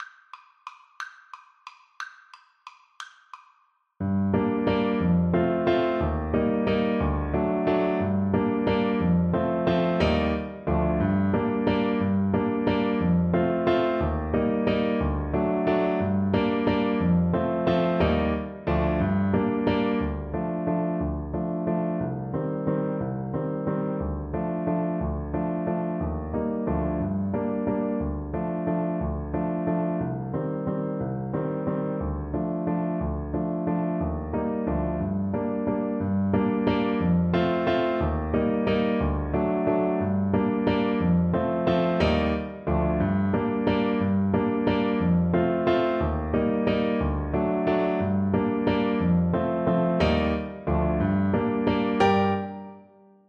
Play (or use space bar on your keyboard) Pause Music Playalong - Piano Accompaniment Playalong Band Accompaniment not yet available transpose reset tempo print settings full screen
G minor (Sounding Pitch) (View more G minor Music for Trombone )
3/4 (View more 3/4 Music)
One in a bar . = c. 60
Traditional (View more Traditional Trombone Music)